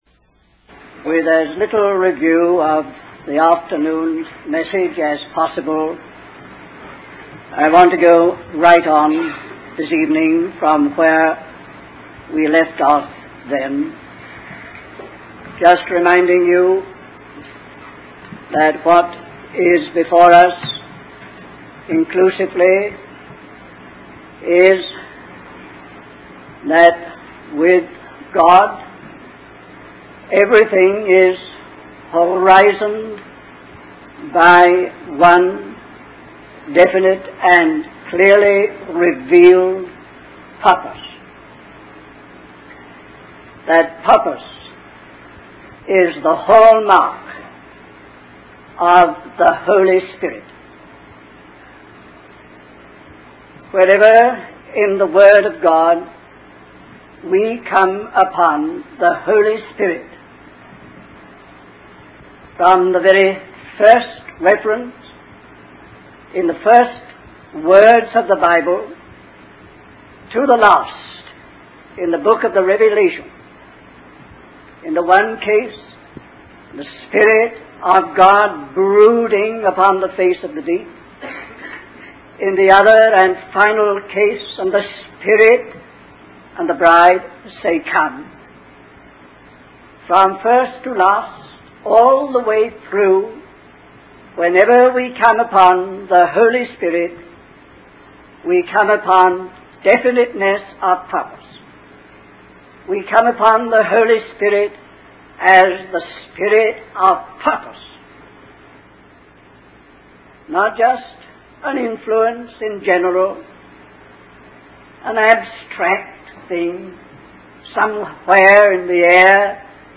In this sermon, the speaker emphasizes the importance of having a clear vision and purpose in the Christian life.